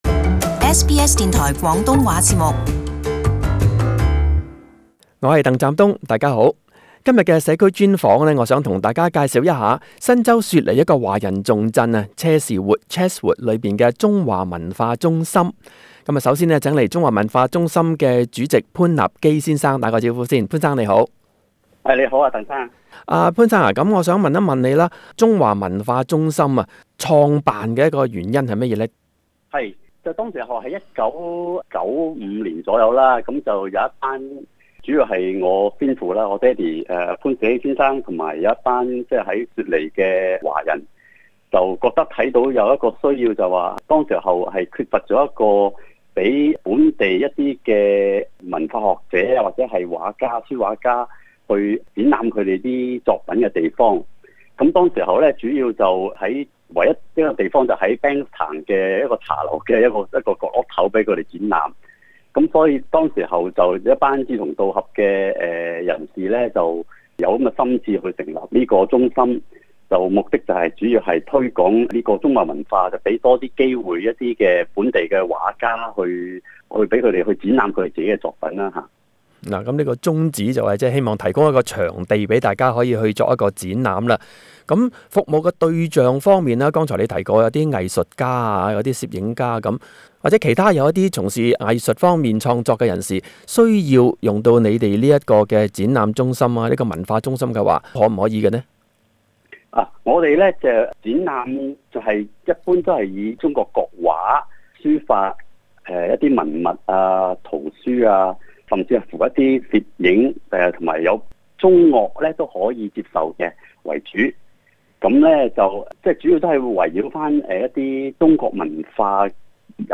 【社區專訪】 俗世清泉 - 中華文化中心